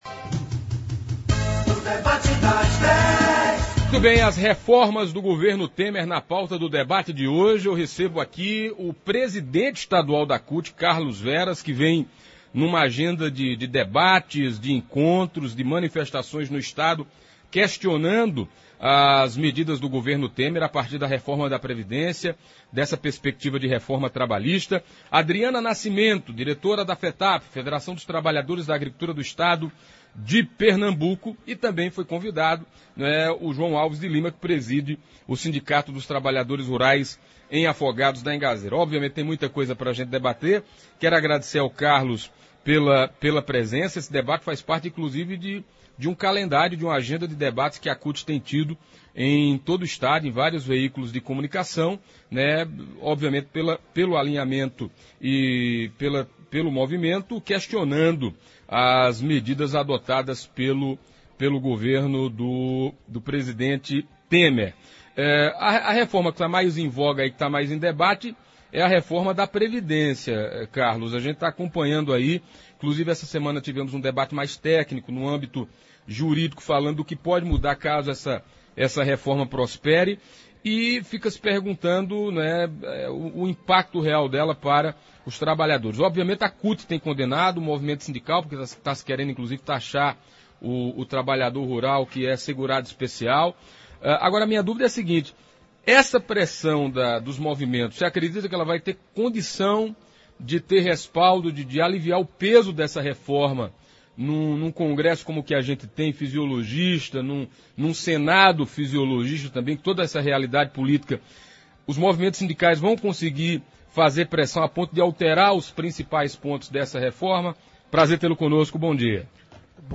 O Presidente Estadual da CUT Carlos Veras foi o convidado do Debate da Dez do Programa Manhã Total, da Rádio Pajeú, falando sobre a luta da entidade contra a Reforma da Previdência e Trabalhista em debate a gestão Temer.